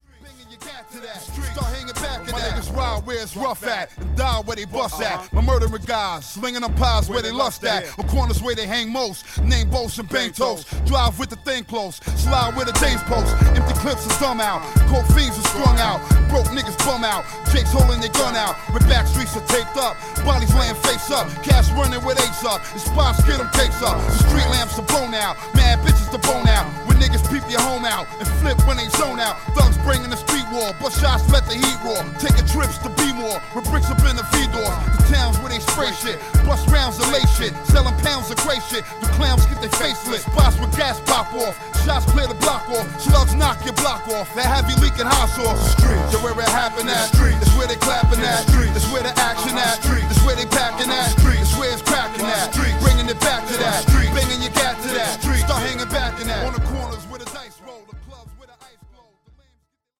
ホーム ｜ HIP HOP
音質は悪くないです。